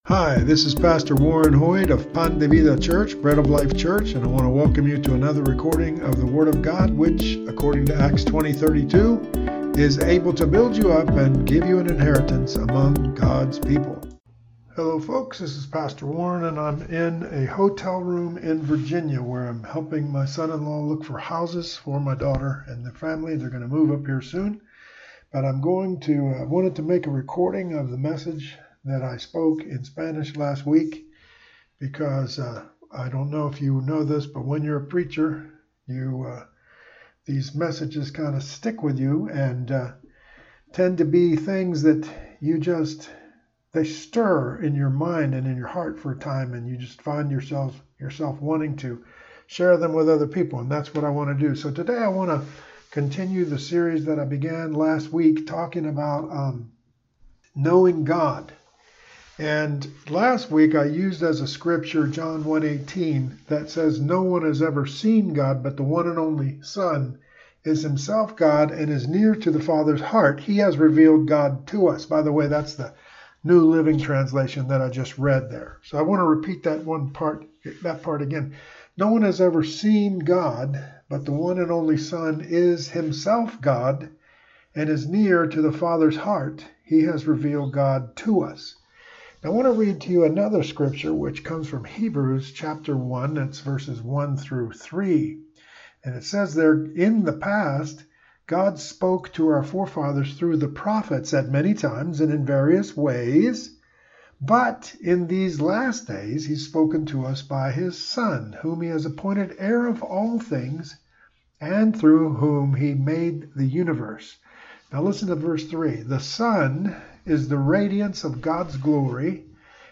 English Sermons